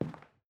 Sounds / sfx / Footsteps / Carpet / Carpet-11.wav
Carpet-11.wav